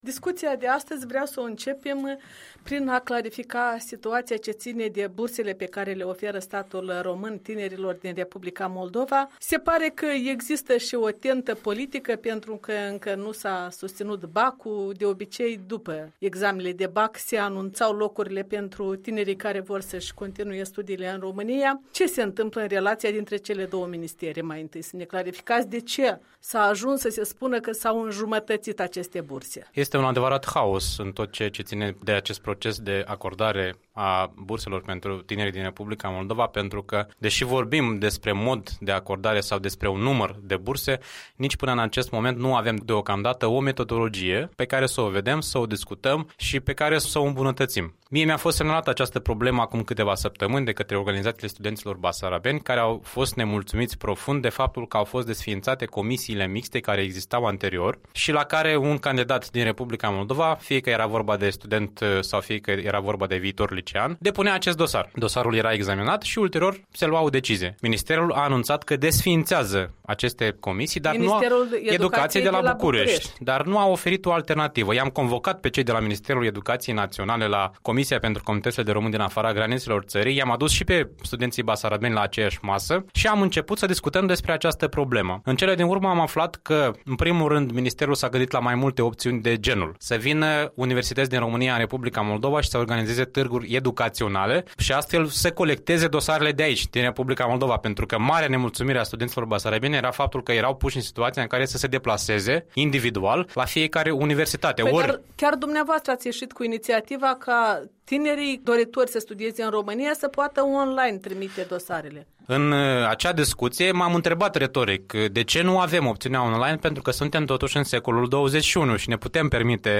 Un interviu cu deputatul român, președinte al Comisiei parlamentare pentru comunitățile de români din afara granițelor țării.